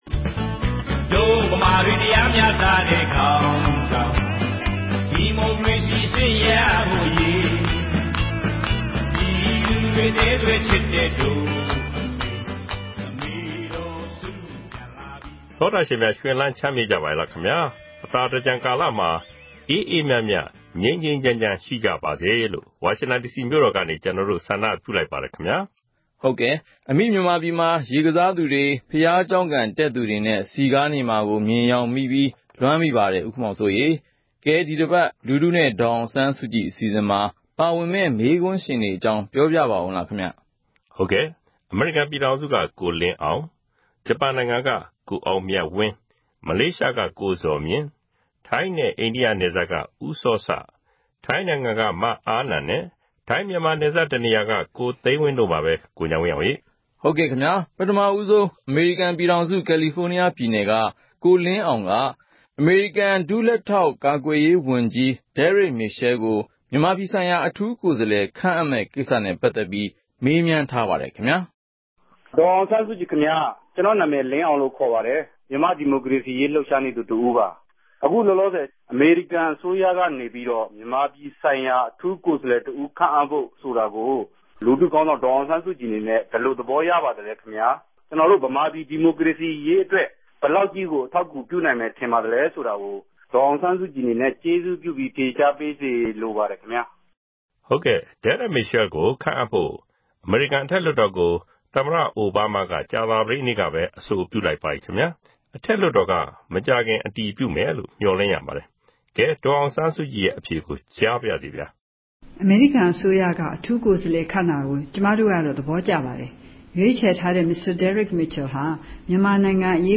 လူထုနဲ့ ဒေါ်အောင်ဆန်းစုကြည် အစီအစဉ်ကို RFA က အပတ်စဉ် သောကြာနေ့ ညတိုင်းနဲ့ ဗုဒ္ဓဟူးနေ့ မနက်တိုင်း တင်ဆက်နေပါတယ်။ ဒီ အစီအစဉ်ကနေ ပြည်သူတွေ သိချင်တဲ့ မေးခွန်းတွေကို ဒေါ်အောင်ဆန်းစုကြည် ကိုယ်တိုင် ဖြေကြားပေးမှာ ဖြစ်ပါတယ်။